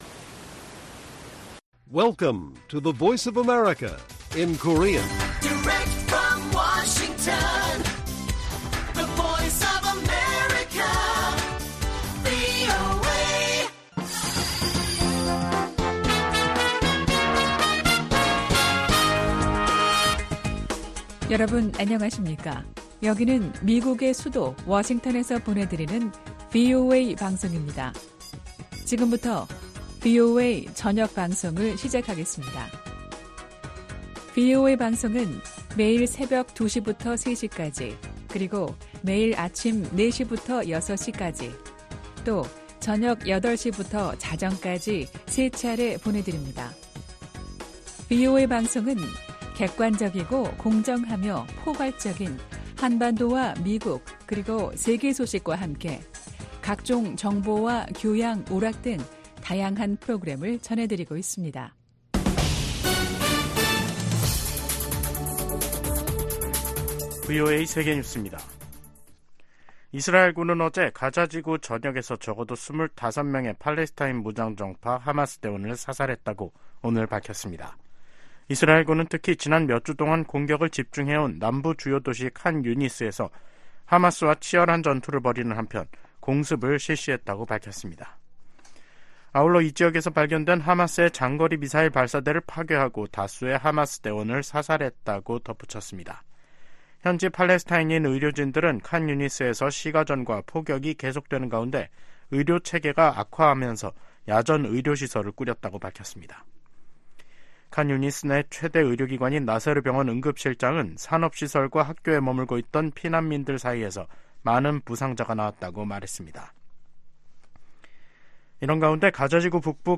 VOA 한국어 간판 뉴스 프로그램 '뉴스 투데이', 2024년 2월 1일 1부 방송입니다. 한국 총선을 겨냥한 북한의 도발 가능성에 한반도 긴장이 고조되고 있습니다. 미 국무부는 최근 중국 외교부 대표단의 방북 직후 북한이 순항미사일을 발사한 점을 지적하며, 도발을 막는데 중국의 역할이 필요하다고 강조했습니다. 미한일 협력이 북한·중국 대응을 넘어 세계 현안을 다루는 협력체로 성장하고 있다고 백악관 국가안보보좌관이 말했습니다.